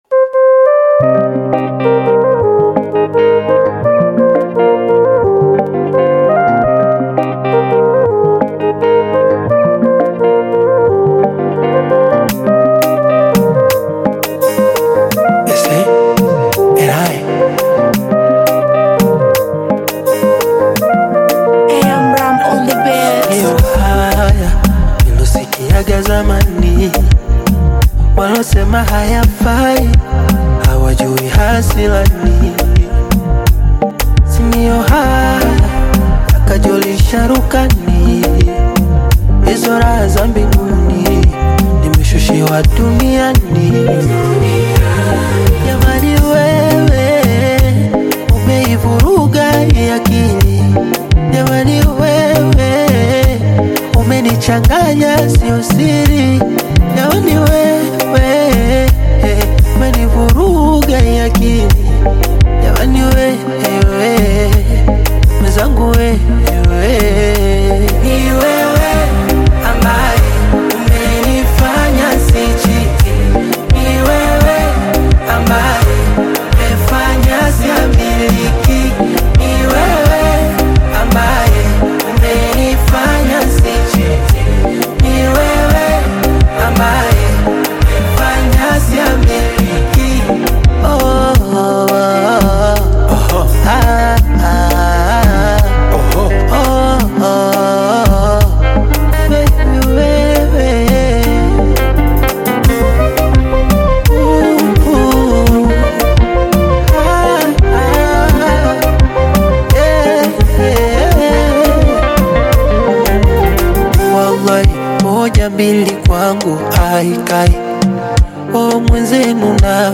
Bongo Flava
Tanzanian Bongo Flava artist, singer, and songwriter